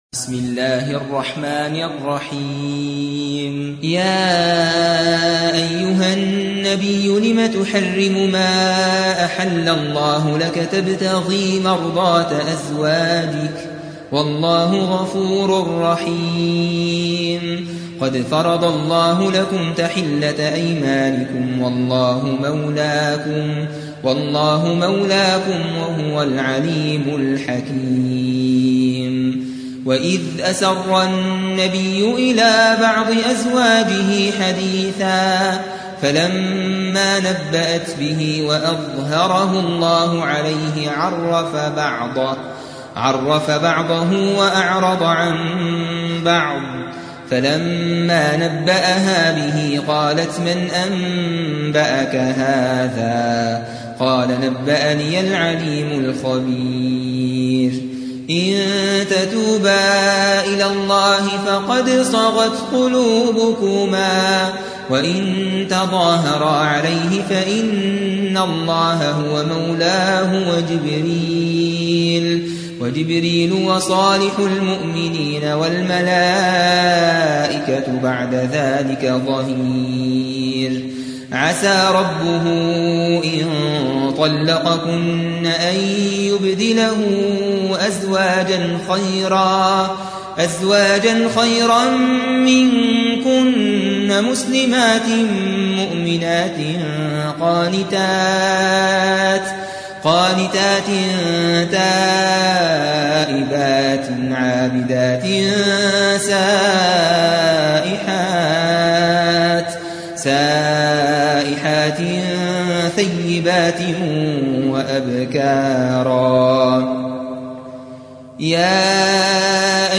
66. سورة التحريم / القارئ